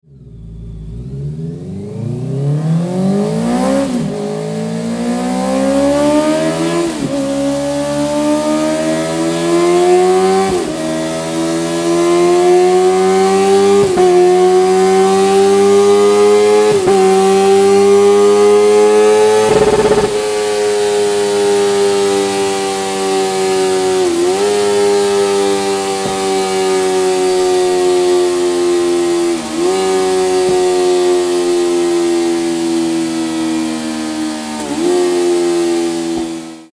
MIVV Komplettanlage AK-1 Titan HONDA CBR 650 R 24-26
Download 2025-06-12-Honda-CBR-650-R-2024-AK1-Audio.mp3